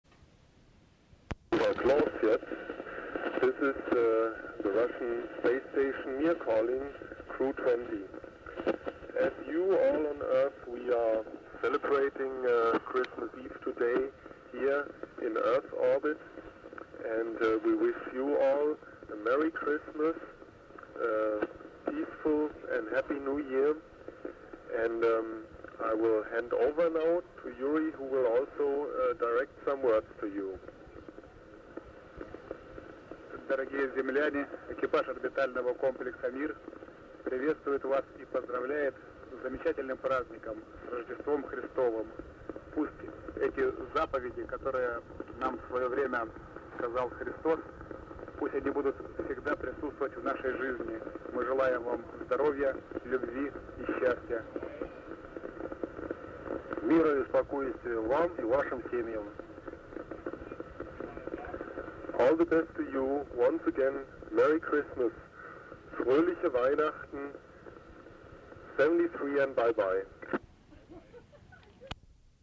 Audio Below was captured as the Mir Space Station Orbited over Maui Hawaii, with HAM operators onboard.